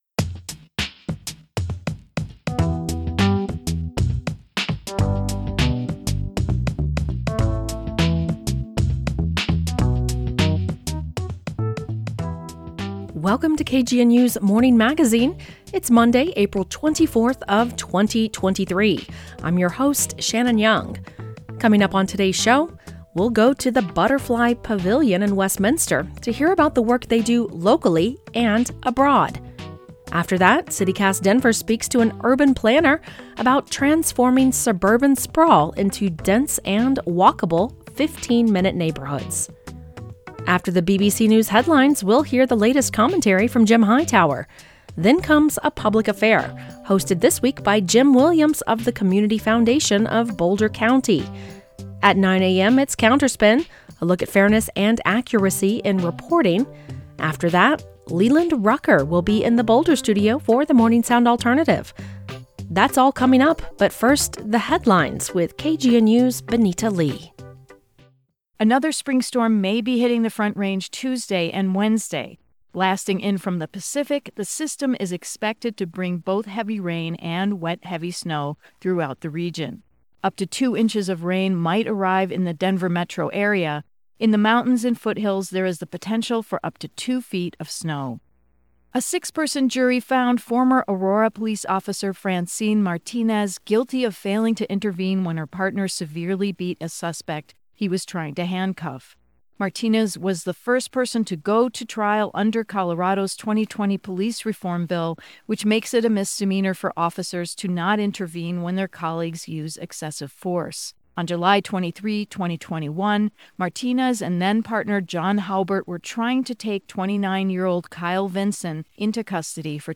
On today’s Morning Magazine, we’ll go to the Butterfly Pavilion in Westminster to hear about the work they do locally and abroad. After that, City Cast Denver speaks to an urban planner about transforming suburban sprawl into dense and walkable “15 minute neighborhoods.”